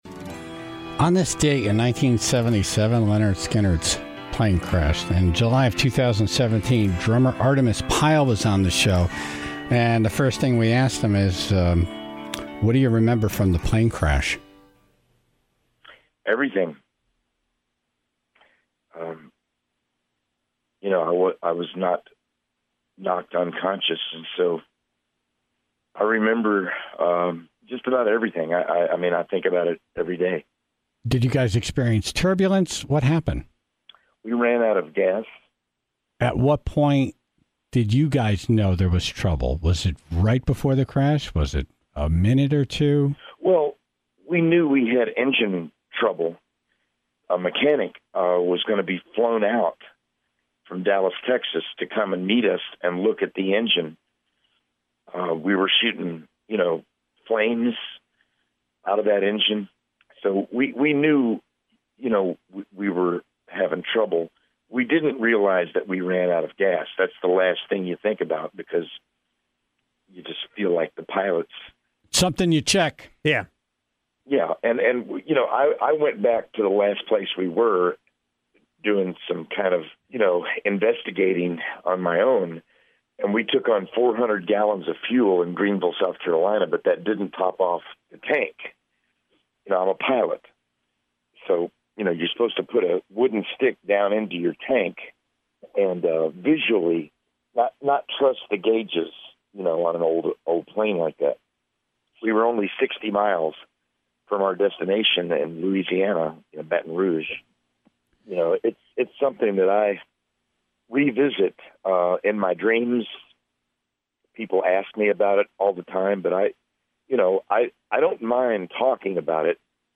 Artimus Pyle before the Milford Oyster Festival